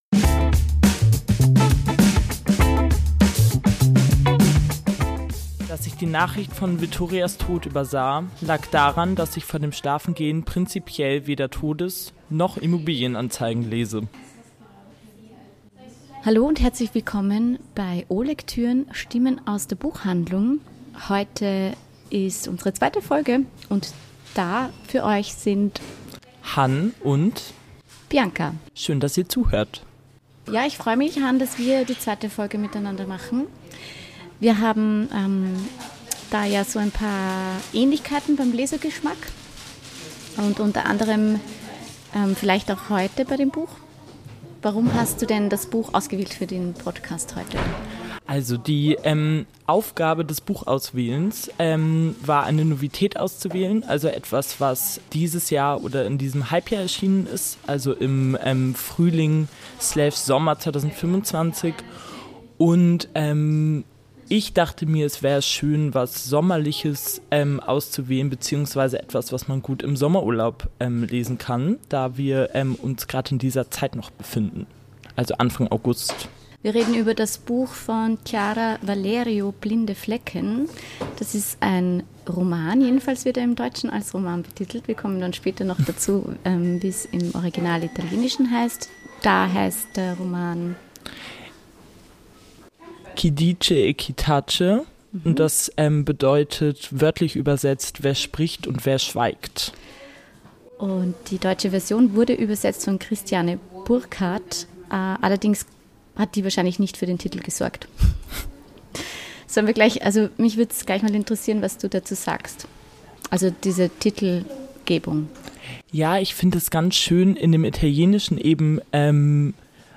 Disclaimer: Die Aufnahme war eine kleine Herausforderung. Ende August, mit der Hitze und all unseren Projekten, war es nicht ganz einfach, die Bedingungen perfekt zu gestalten.
Wir versprechen, dass die nächste Folge wieder unter besseren Bedingungen stattfinden wird.